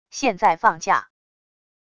现在放假wav音频生成系统WAV Audio Player